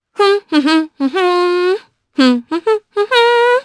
Naila-Vox_Hum_jp.wav